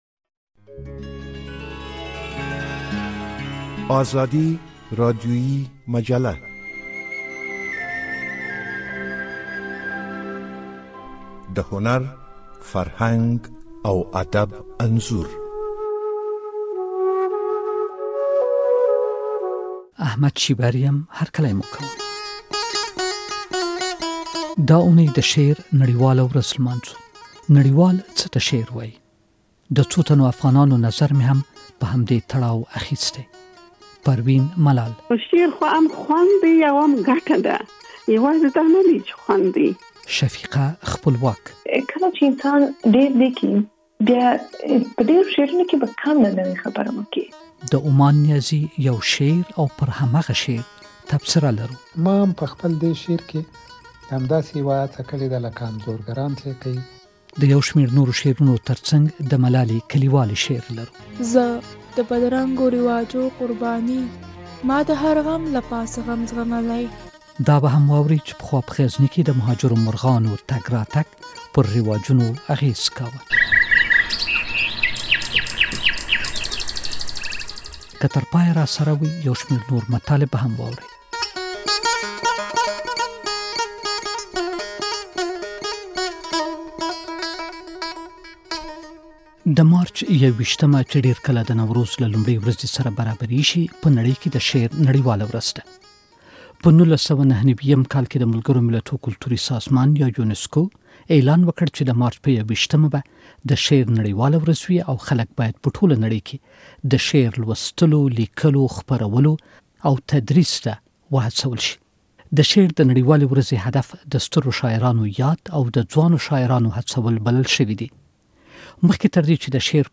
د ازادي راډیویي مجلې په دې اوونیزه خپرونه کې د شعر نړیواله ورځ لمانځو. د شعر په اړه مو هم د نړیوالو له نظره خبرو، هم له افغان شاعرانو سره غږیدلي یوو. یو شمېر شعرونه هم خپروو.